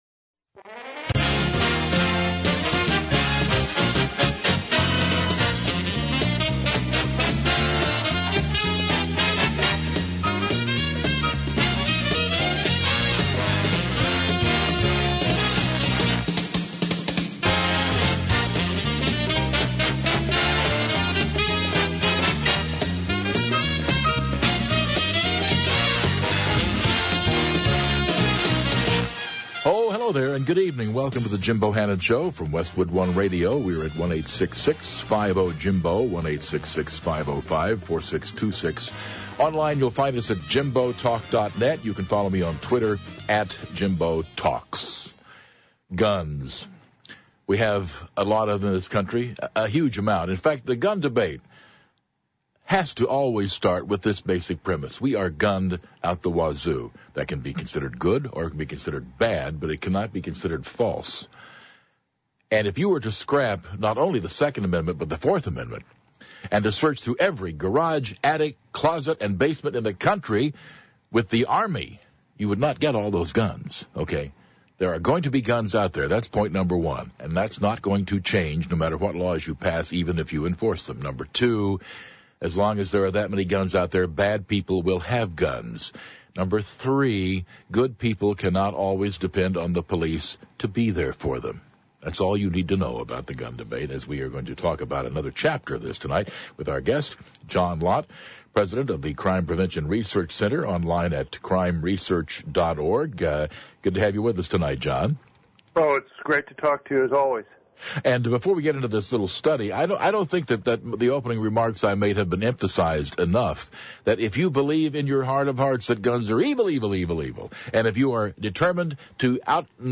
CPRC on the Jim Bohannon radio show to discuss new claims about police deaths and gun ownership